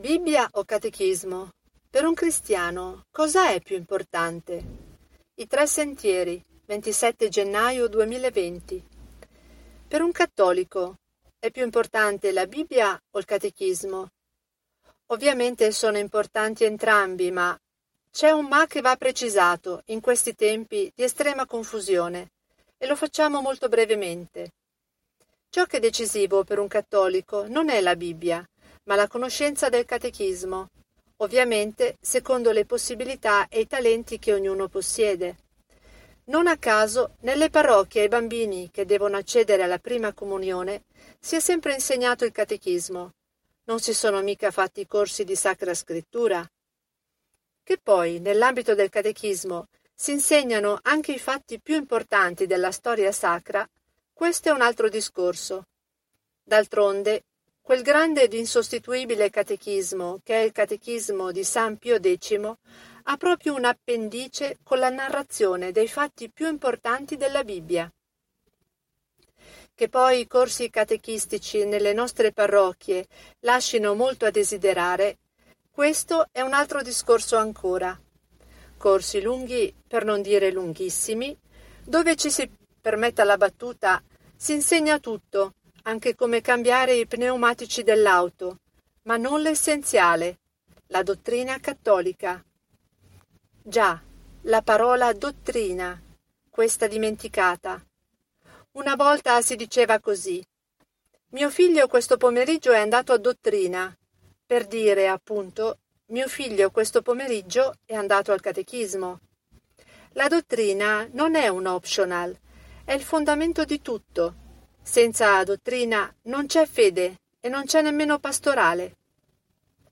Clicca per la Lettura Automatica ASCOLTA Leggo per te